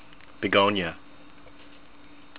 be-GON-ee-uh